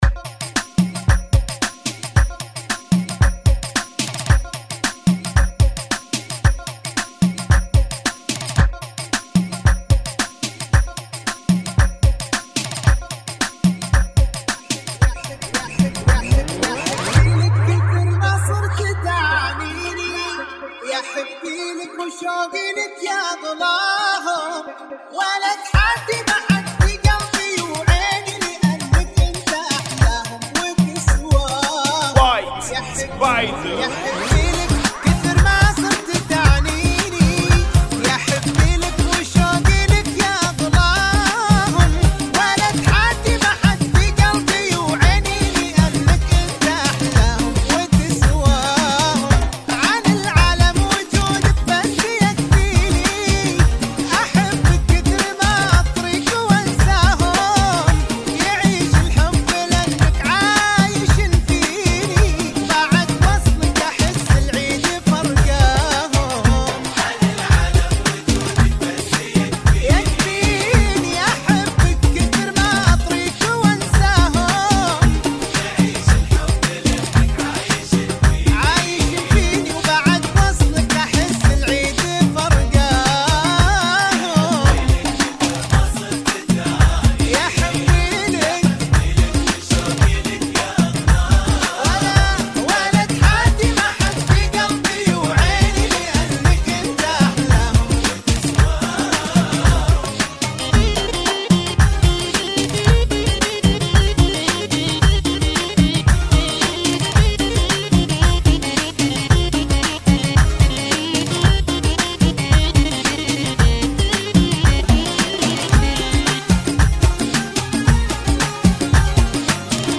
Funky [ 112 Bpm ]